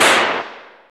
SI2 SHOT  02.wav